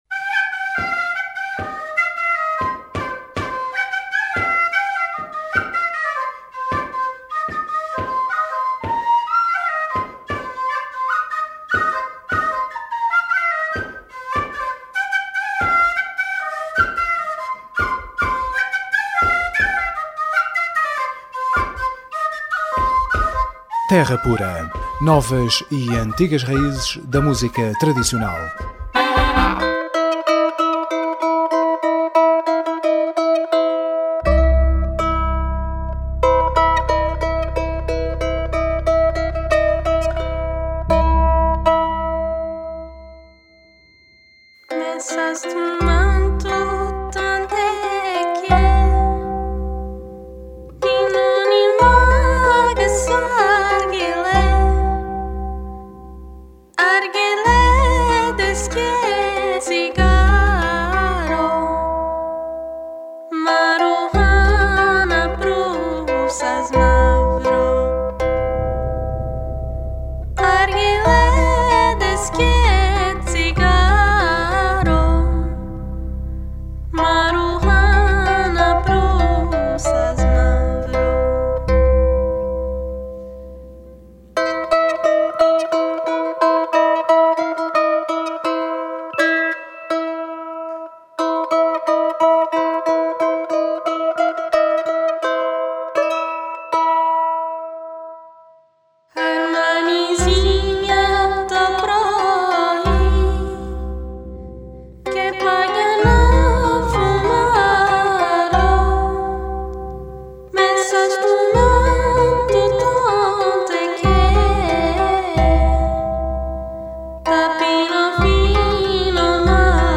Terra Pura 02JAN12: Entrevista